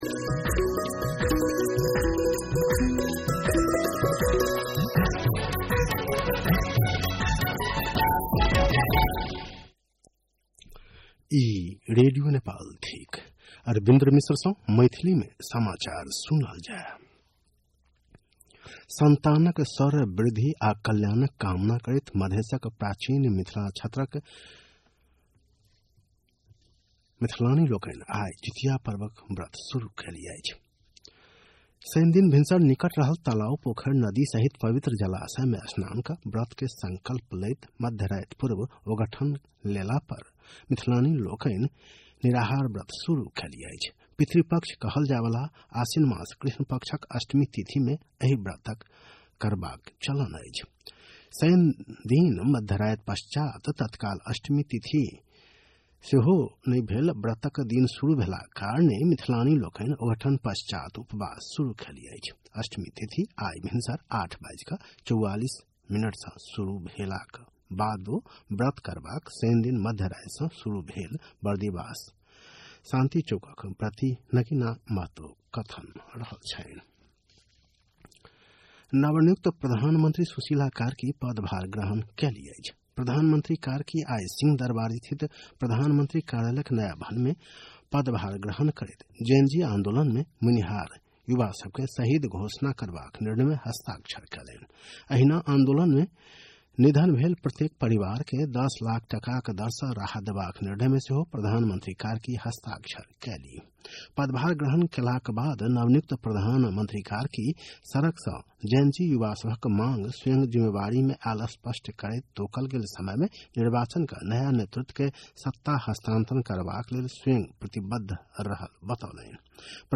मैथिली भाषामा समाचार : २९ भदौ , २०८२